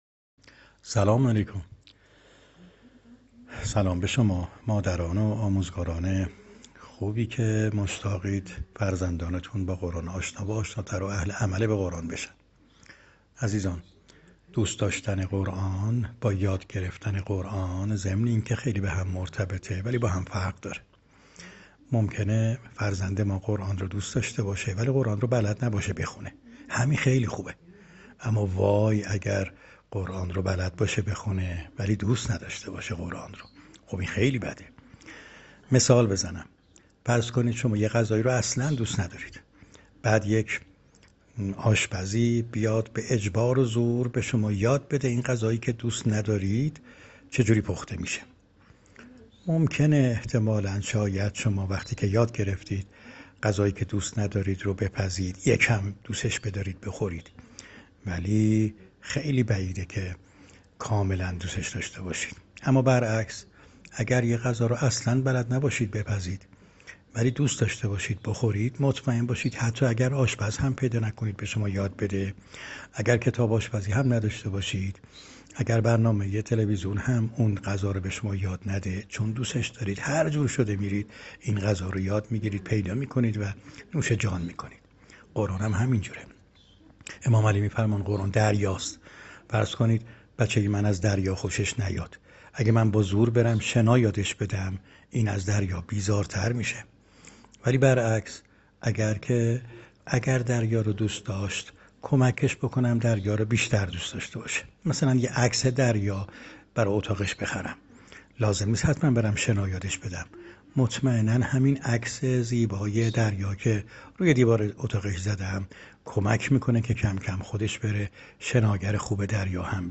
پاسخ صوتی